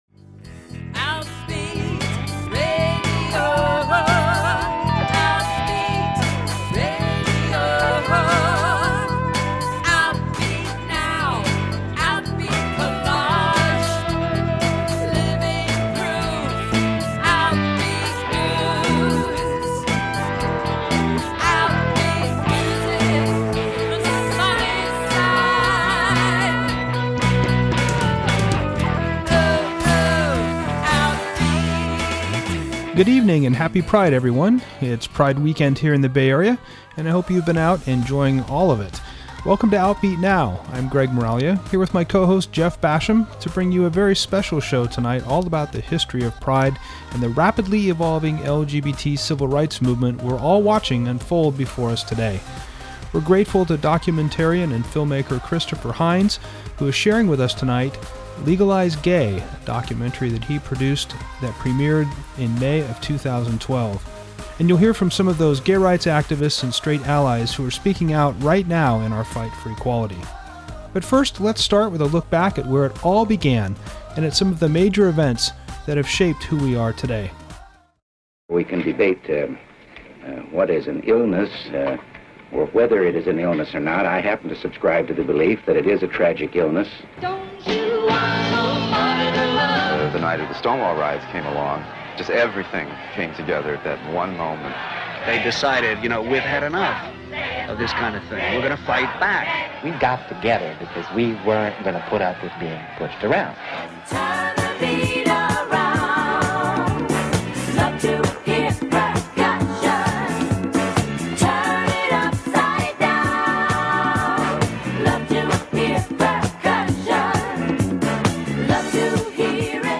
Outbeat Radio is a weekly radio program for and about the lesbian, gay, bisexual and transgender community in the California North Bay airing on KRCB Radio 91.1 FM
Â Our show features interviews with Gilbert Baker, the creator of the gay flag, and a premier of several segments from the Christopher Hines documentary, “Legalize Gay.”